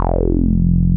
RUBBER F2 F.wav